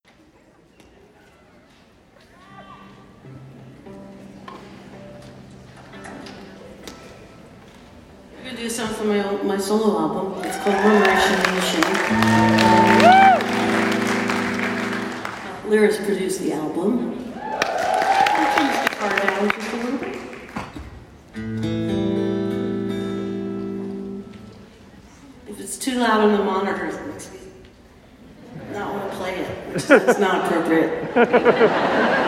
17. talking with the crowd (0:32)